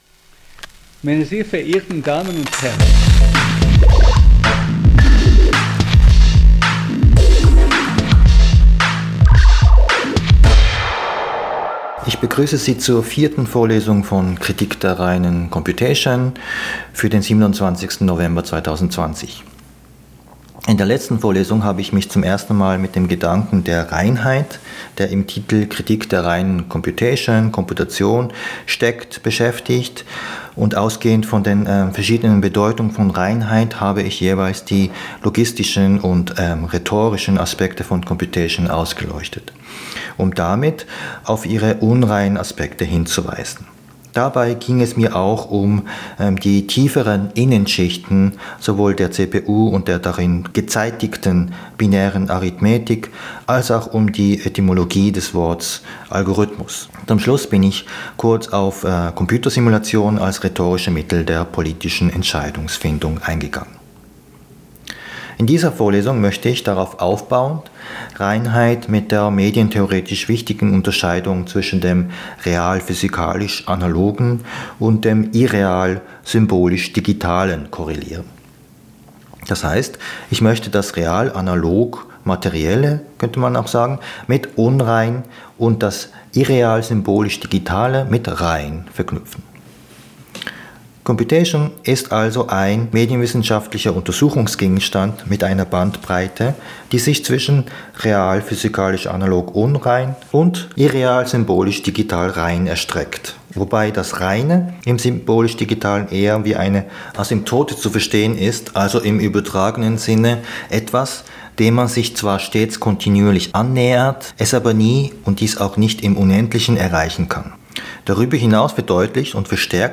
Podcastvorlesungen